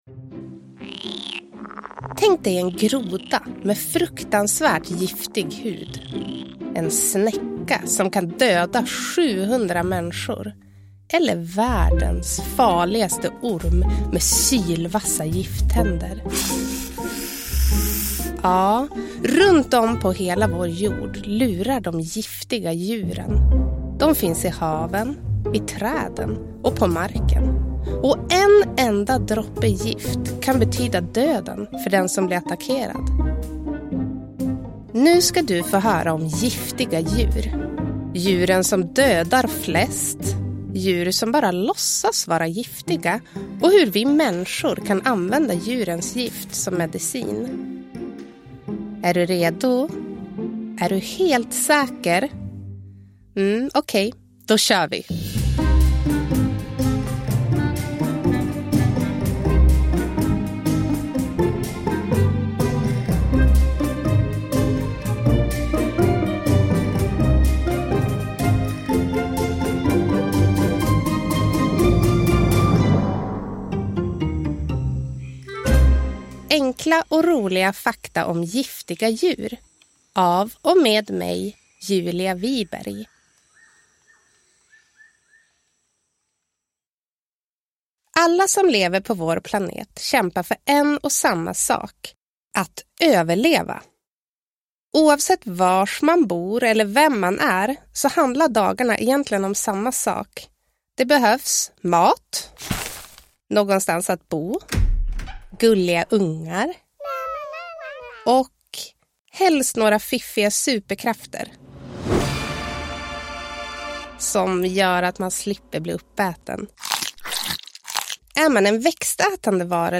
Enkla och roliga fakta om giftiga djur – Ljudbok